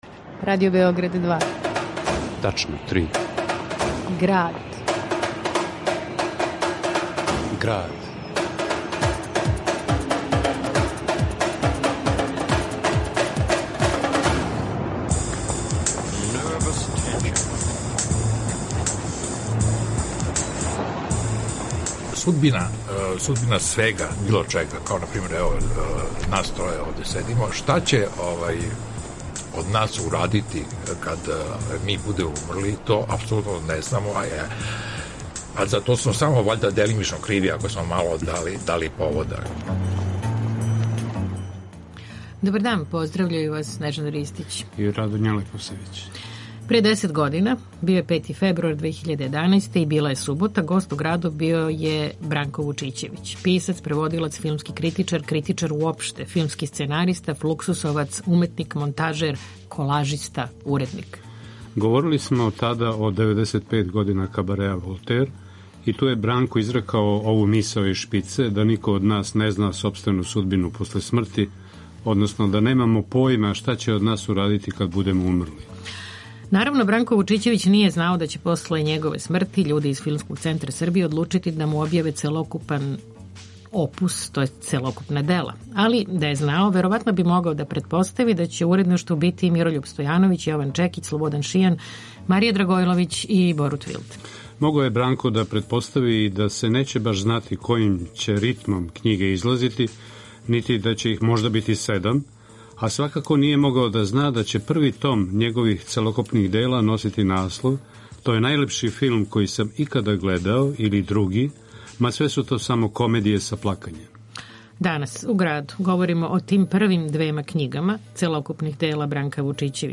уз архивске снимке